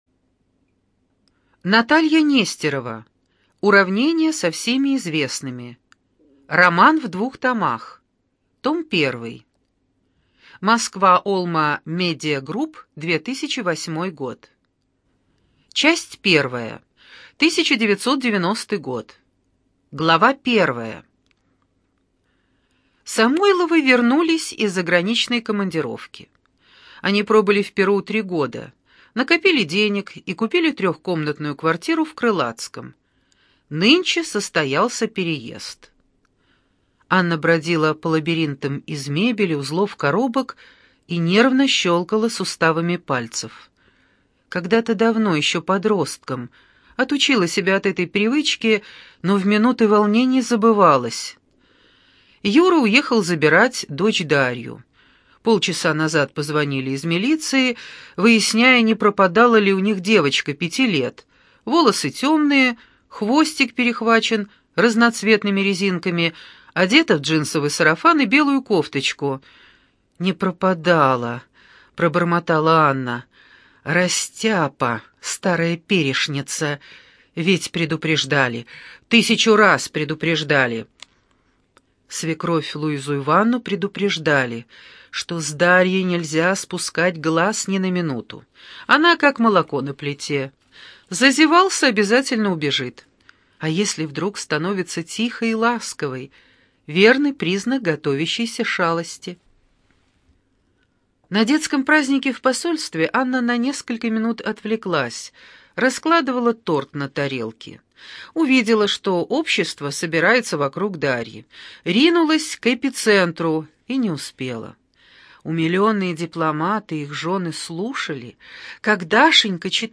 ЖанрЛюбовная проза
Студия звукозаписиЛогосвос